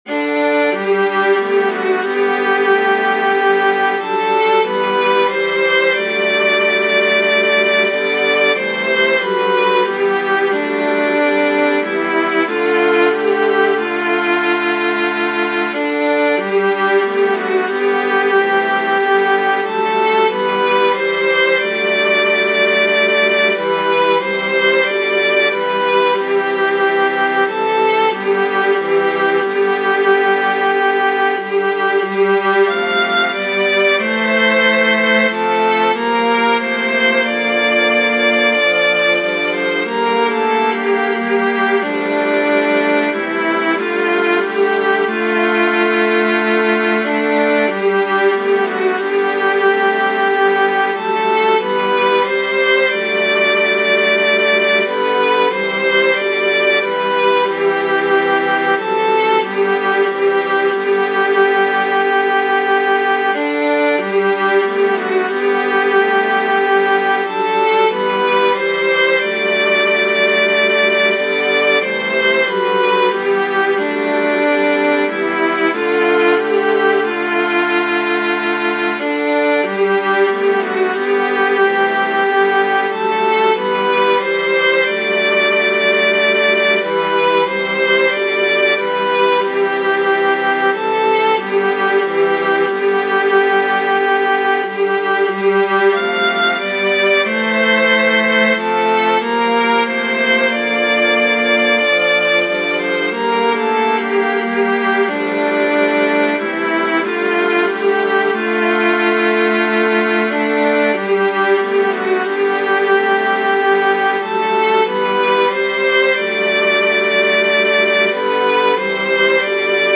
As an example, I brought the latest MXL version into MuseScore, changed all parts to viola (being the violacentric kinda guy that I am), and reduced the volume by 10% on parts 2-4 and by 5% on part 5.
And here it is with part 1 taken by violin:
Wexford_Carol-Project-1-Violin-All.mp3